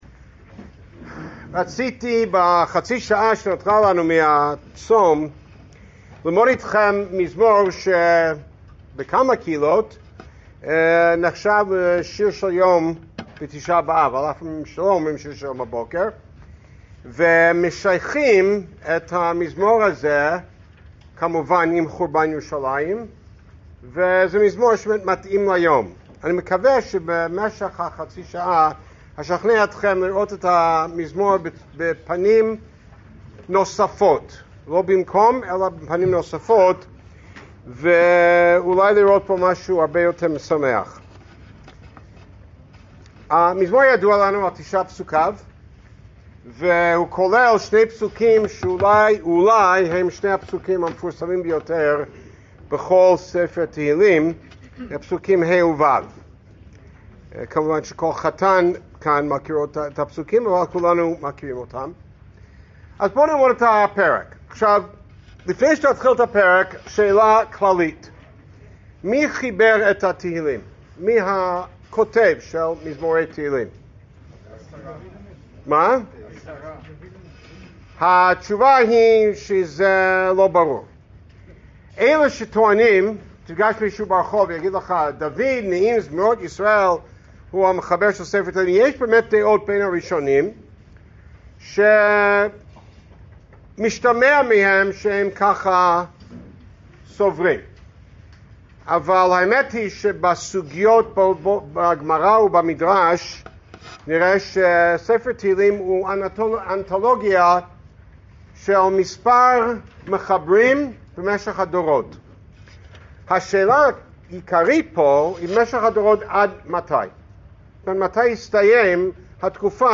השיעור ניתן בבית הכנסת "אהל ארי" ברעננה בתום צום תשעה באב.